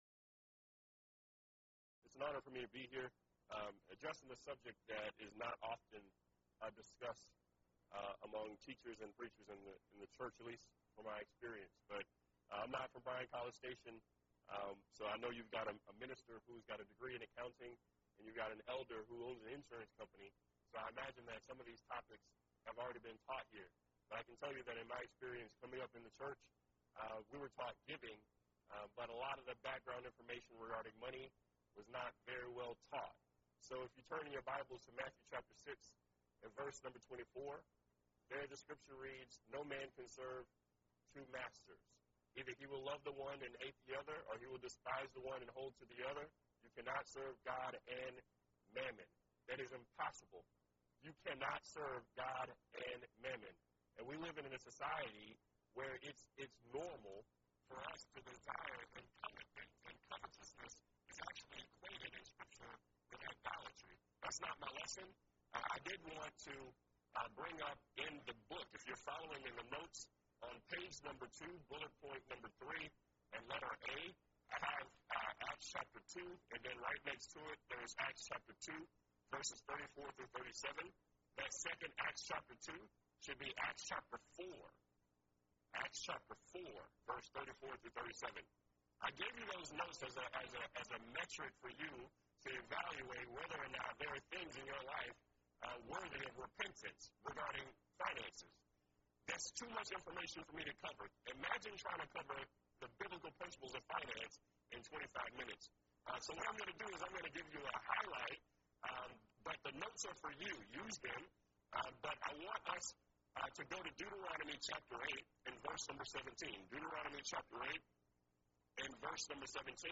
Event: 2nd Annual Young Men's Development Conference
lecture